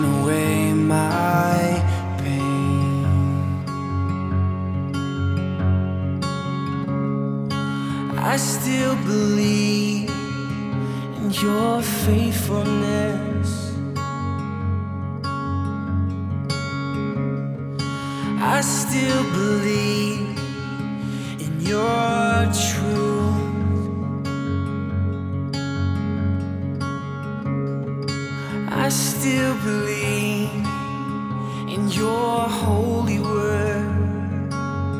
# Christian & Gospel